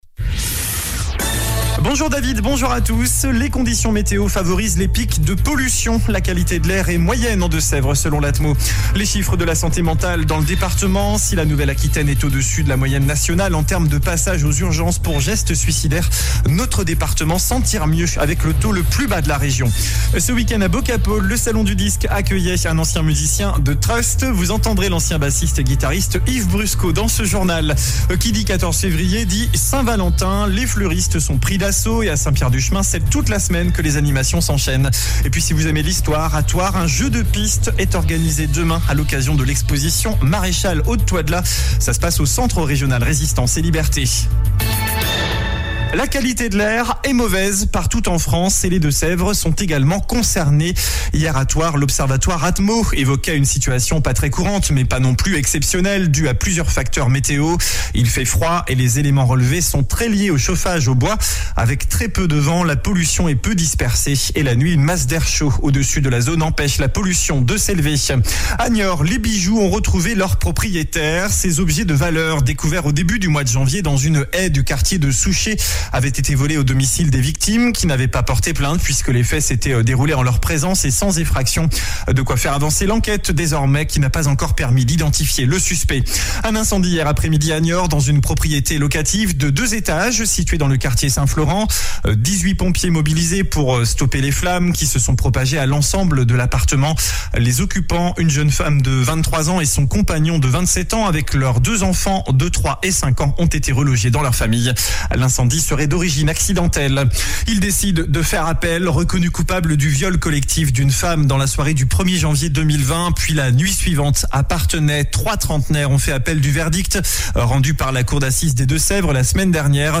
JOURNAL DU MARDI 14 FEVRIER ( MIDI )